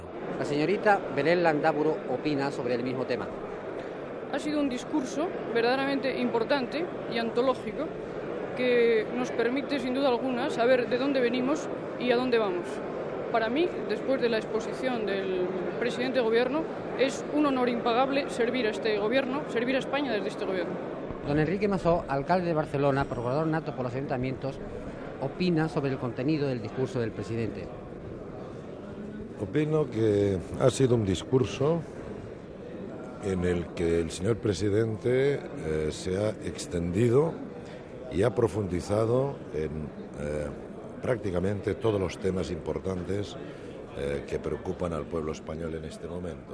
Valoració de Belén Landáburu, Procuradora en Cortes de representació familiar escollida per la província de Burgos, i de l'alcalde de Barcelona Enrique Masó sobre el discurs del president del govern Carlos Arias Navarro als Procuradores en Cortes
Informatiu